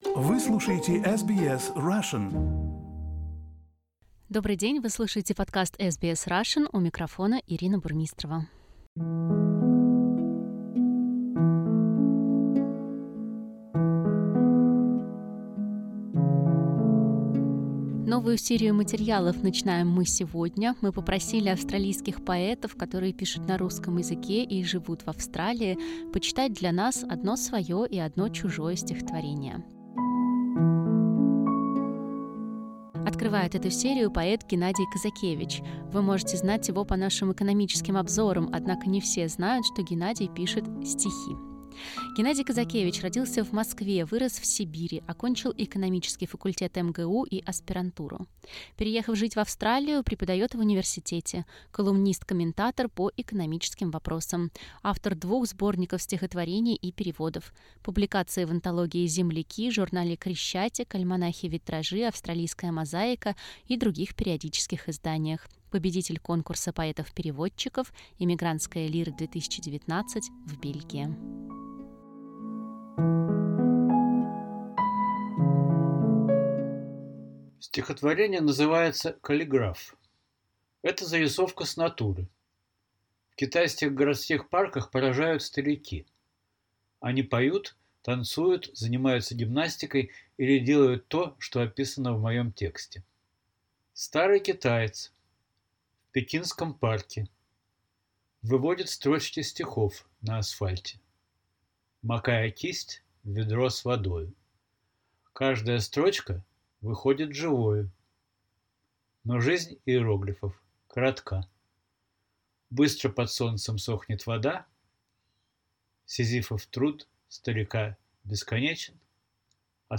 В этой рубрике мы просим австралийских поэтов прочесть два стихотворения: одно свое и одно чужое.
Поэты и писатели читают свои любимые эмигрантские стихи о России